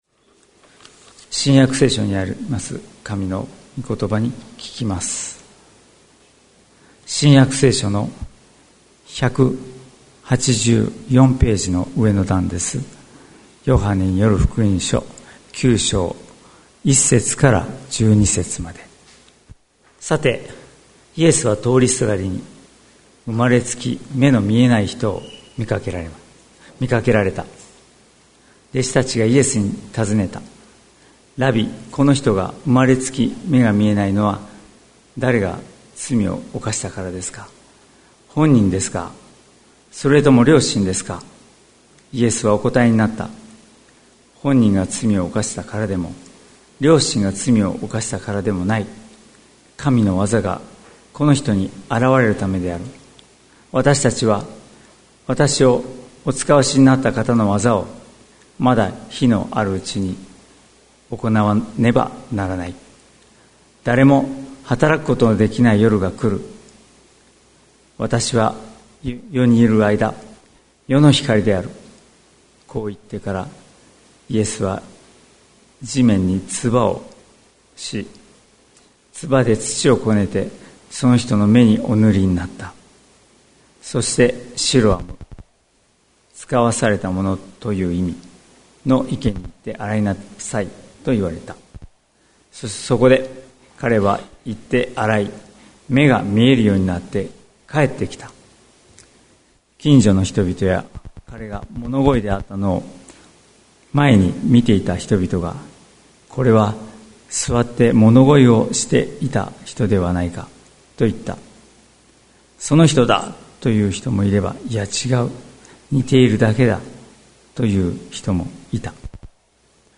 2022年09月04日朝の礼拝「過去から未来へ」関キリスト教会
説教アーカイブ。